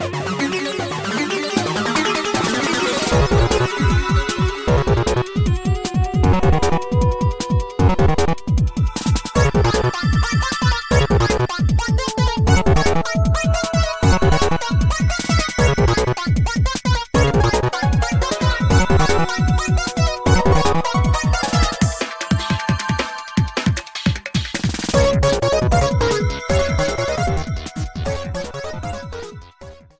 Trimmed to 30s, fadeout